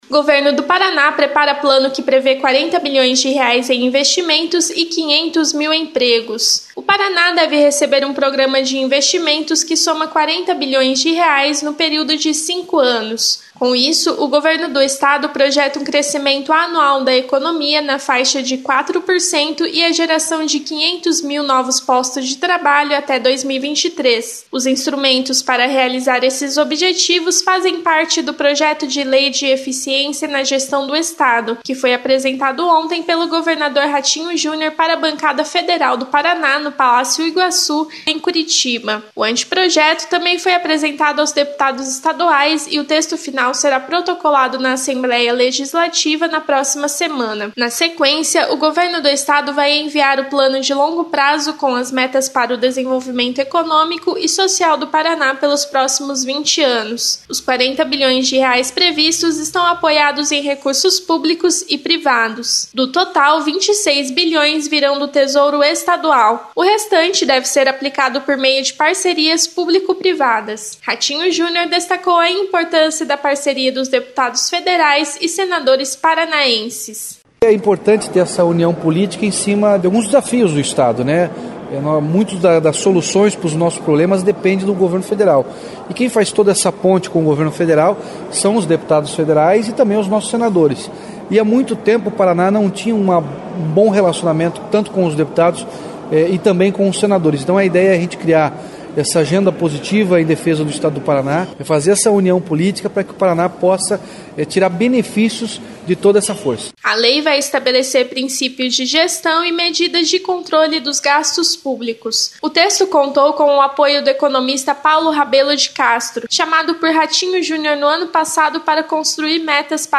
Mais detalhes na programação da Rádio Cultura AM 930